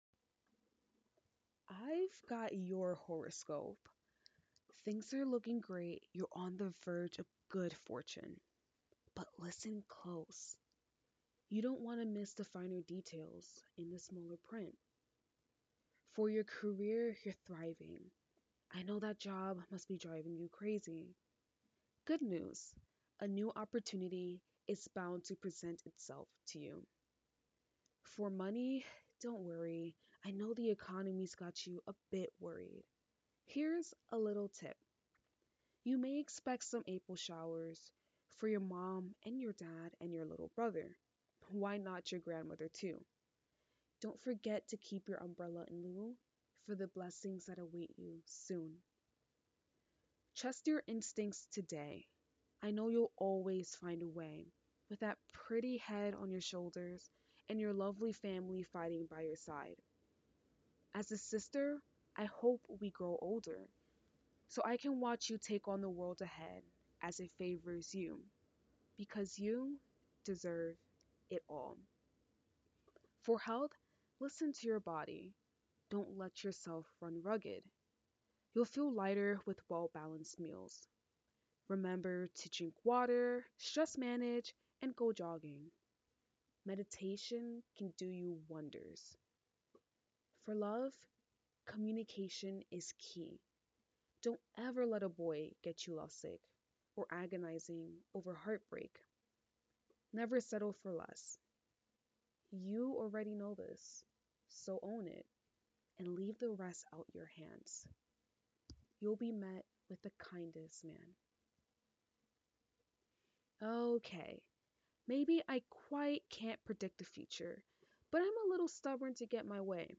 spoken word (demo)